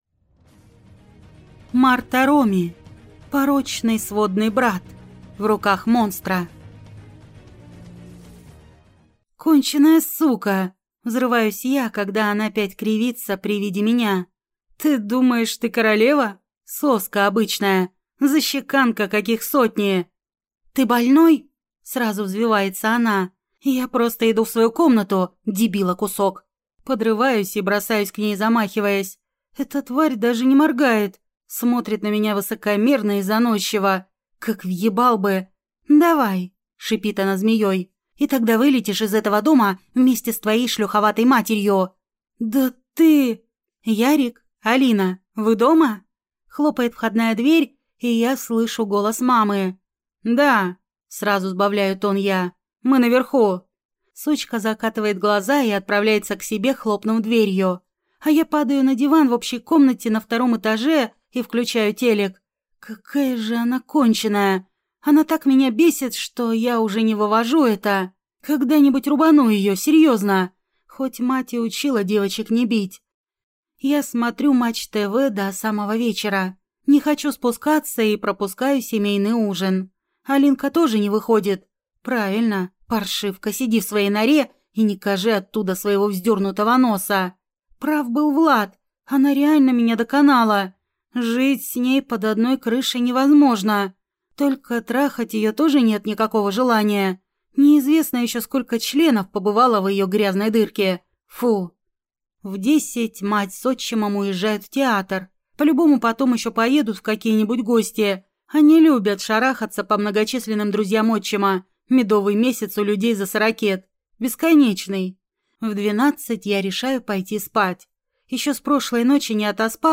Аудиокнига Порочный сводный брат. В руках монстра | Библиотека аудиокниг
Прослушать и бесплатно скачать фрагмент аудиокниги